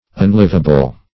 unliveable - definition of unliveable - synonyms, pronunciation, spelling from Free Dictionary